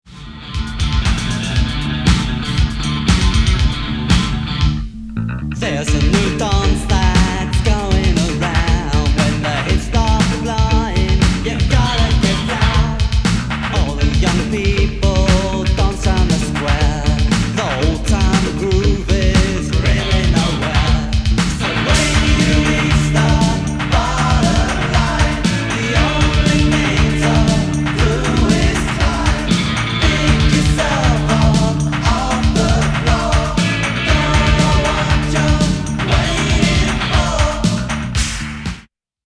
ギター＆カウベルのイントロが長い12”。
コラージュ＆エフェクタブルなディスコ・ロック名曲